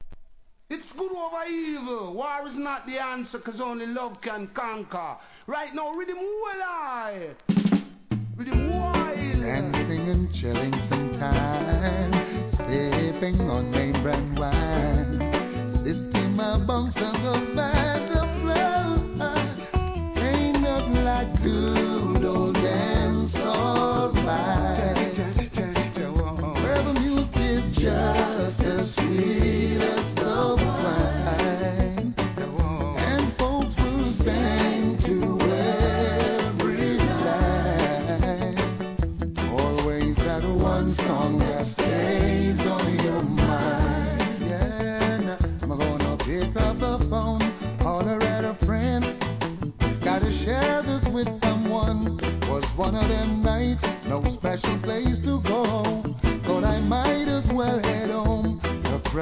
REGGAE
本作もソウルフルなVOCALで聴かせます!